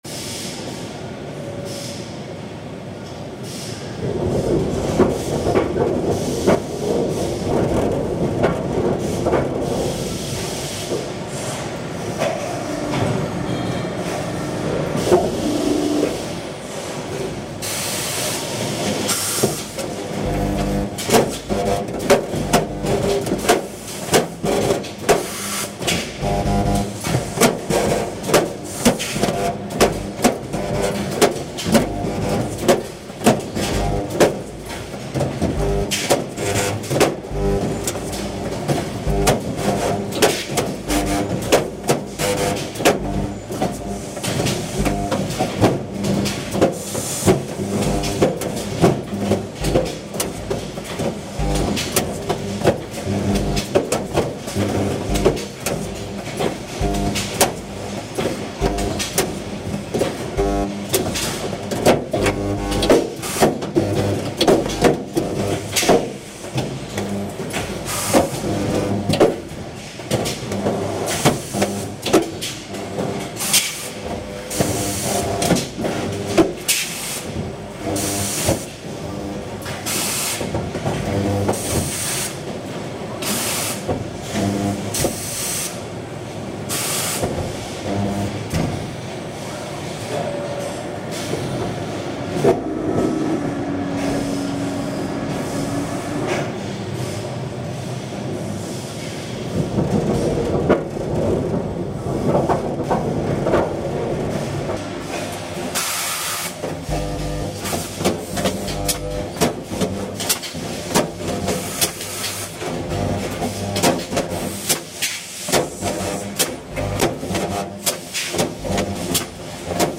Recorded at the Jaguars car manufacturing plant, Liverpool, UK, 2003
The machines are beating and bending metal to a somewhat set time, but things come and go, different parts change, just like in a real song.
Genre:  Field Recordings / Drone Ambient
The robotic laborers buzz, click, drill, and pound their way through making what I assume is the frame of a car.
It’s a locked grove of mechanical hands, never losing time or missing a beat.
The track ends mysteriously with the sound of lounge music being played on the overhead P.A. speakers, while the machines are cooling down emitting strange hiss’.